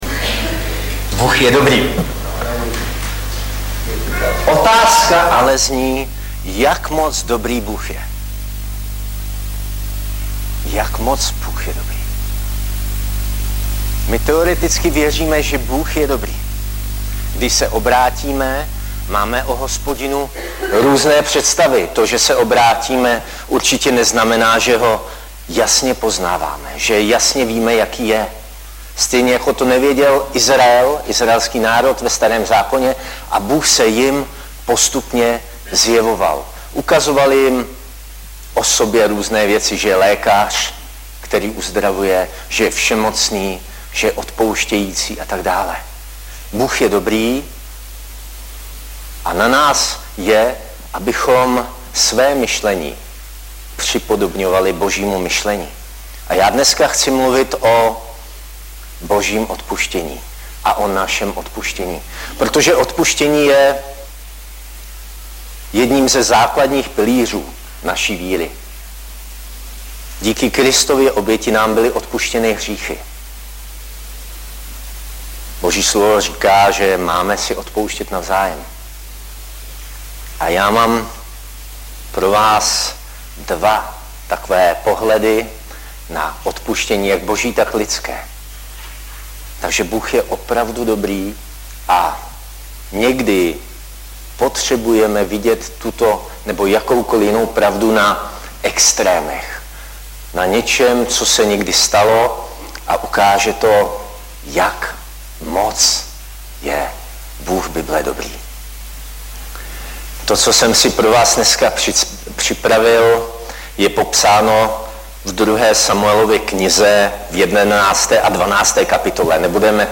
Audiozáznam kázání si můžete také uložit do PC na tomto odkazu.